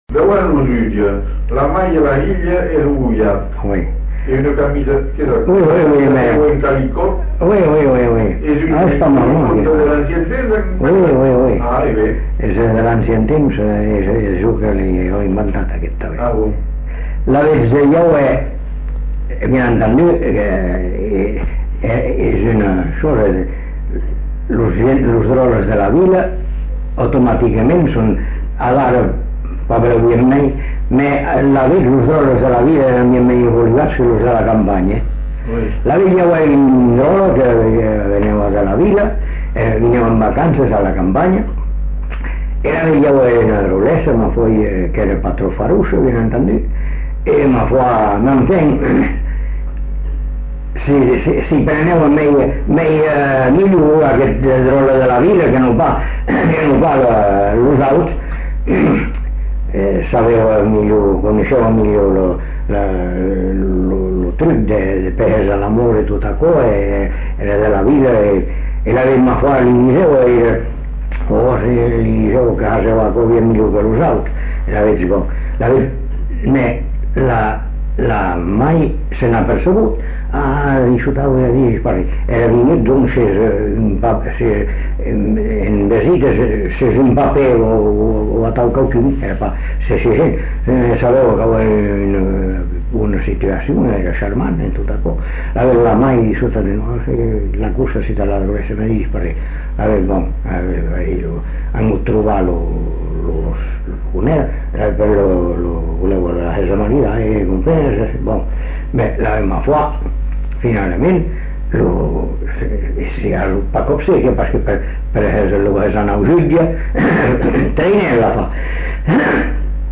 Lieu : Bazas
Genre : conte-légende-récit
Effectif : 1
Type de voix : voix d'homme
Production du son : parlé